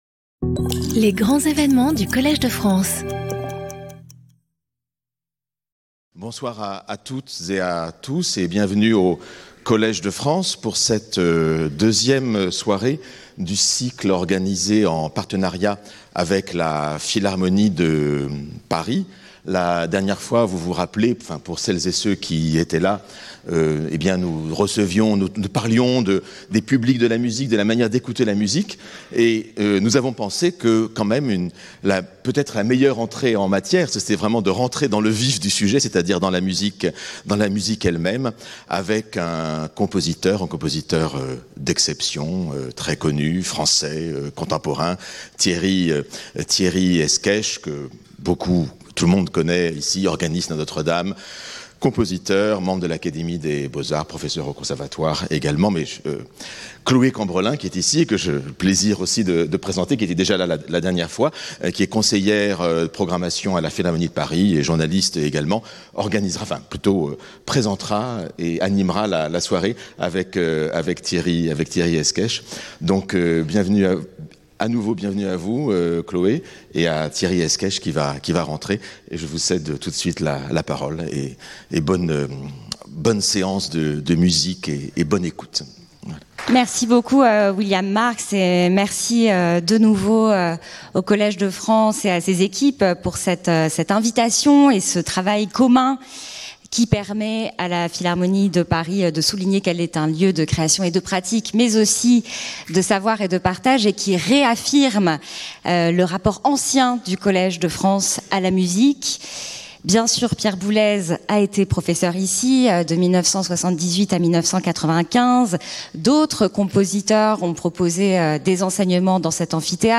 Skip youtube video player Listen to audio Download audio Audio recording Masterclass with Thierry Escaich , organist and composer.
This lecture-masterclass at the piano will present the contemporary challenges of composition: the creation of new works in a variety of formats is one of the missions of the Cité de la musique-Philharmonie de Paris.